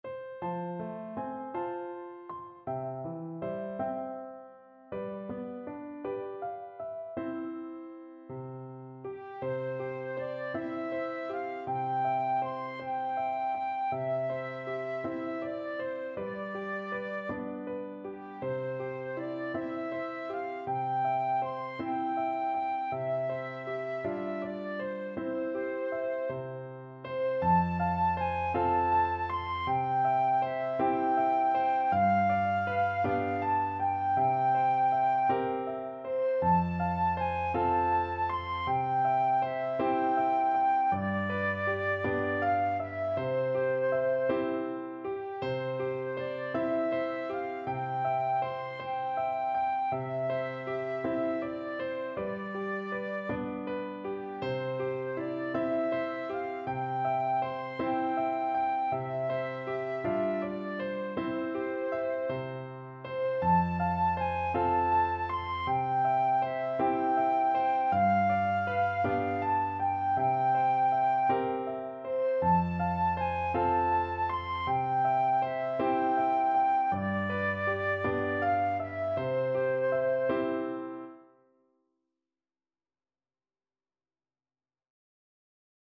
Flute version
6/8 (View more 6/8 Music)
Andante espressivo